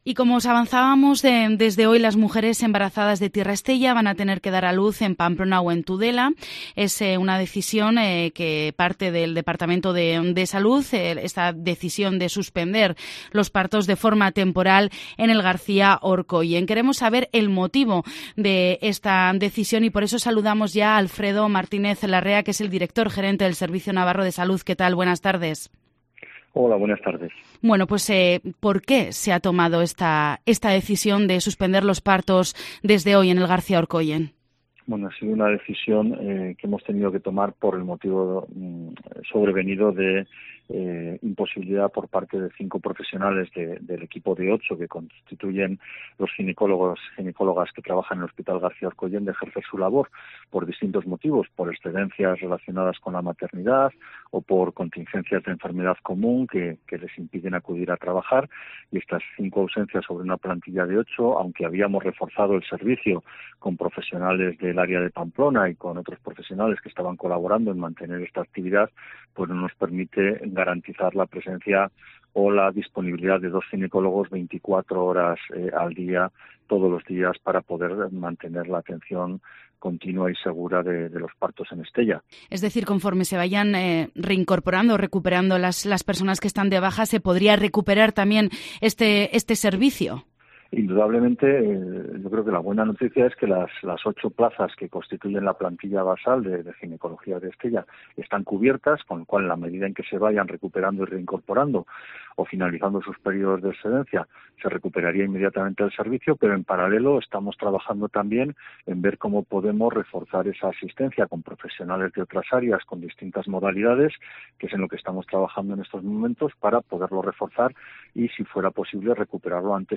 Entrevista con Alfredo Martínez Larrea, director gerente del Servicio Navarro de Salud.